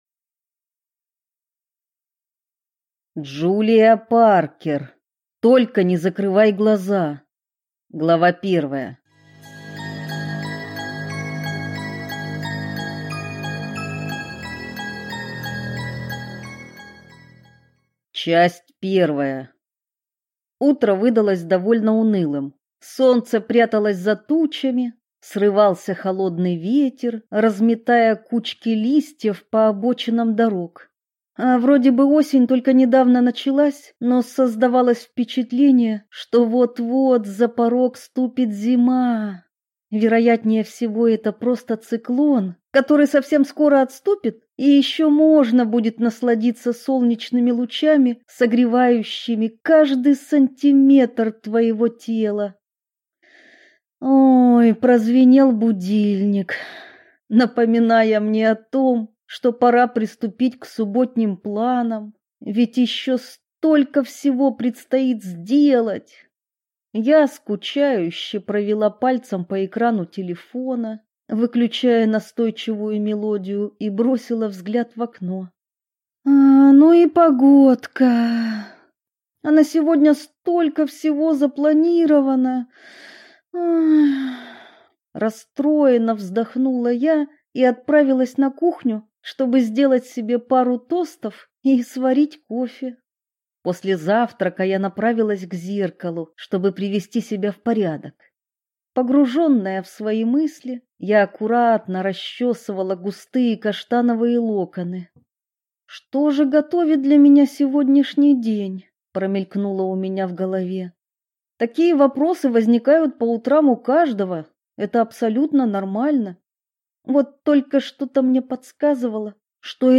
Аудиокнига Только не закрывай глаза | Библиотека аудиокниг